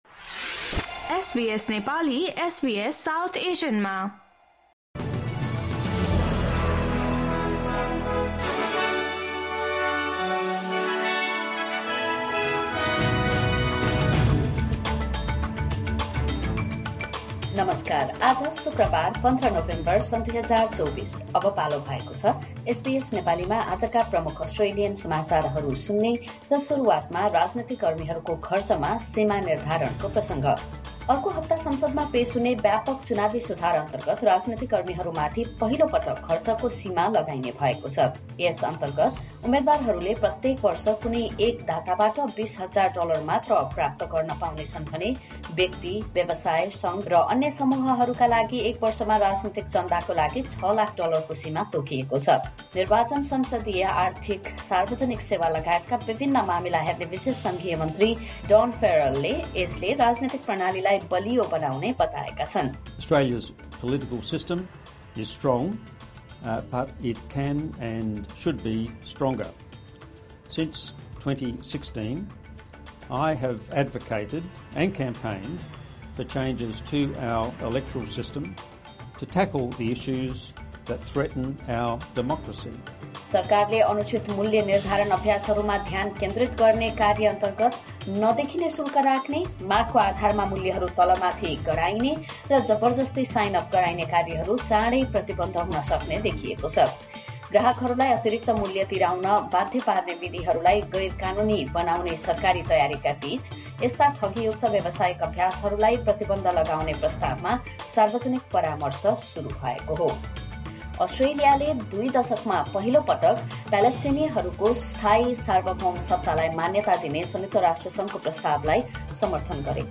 SBS Nepali Australian News Headlines: Friday, 15 November 2024